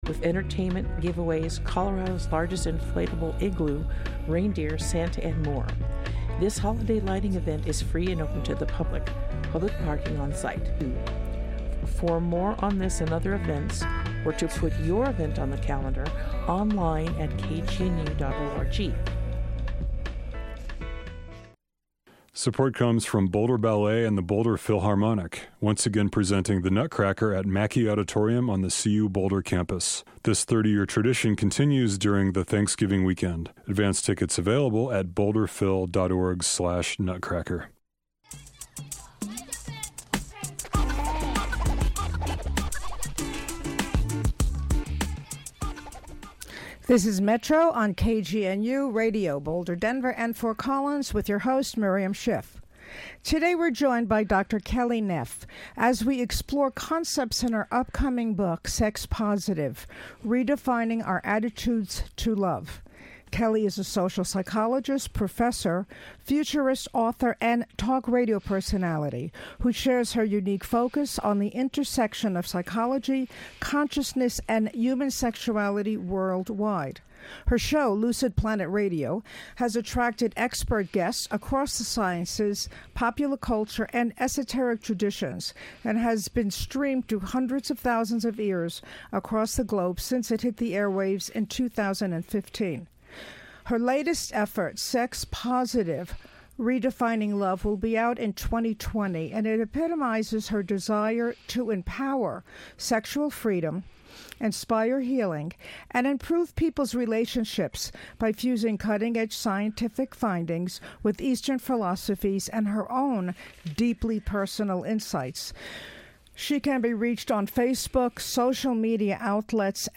talk on KGNU Boulder/Denver